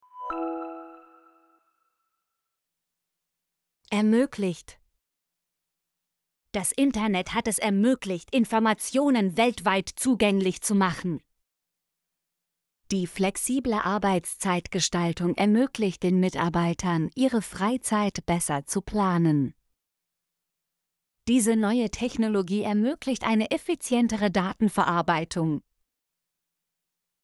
ermöglicht - Example Sentences & Pronunciation, German Frequency List